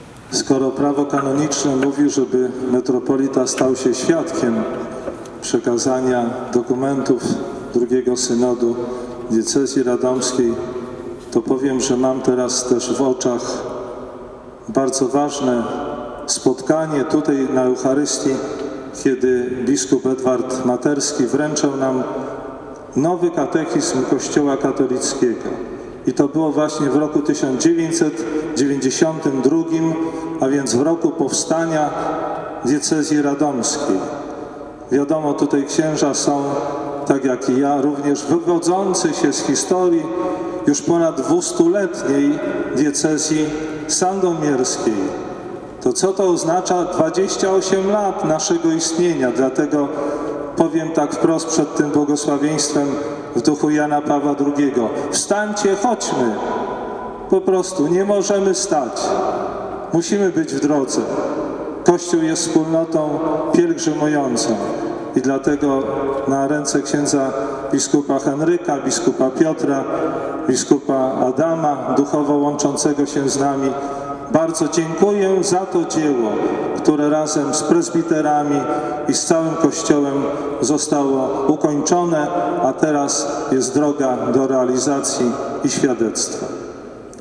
Dziękując za dar II Synodu Diecezji Radomskiej Abp Wacław Depo przekazał do radomskiej katedry monstrancję, a udzielając pasterskiego błogosławieństwa na zakończenie Mszy św. nawiązał do roku 1992, czyli roku podpisania Katechizmu Kościoła Katolickiego i początków Diecezji Radomskiej.
Abp Wacław Depo, zakończenie: